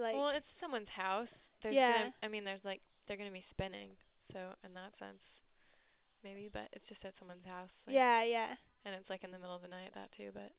Prosodic Patterns in English Conversation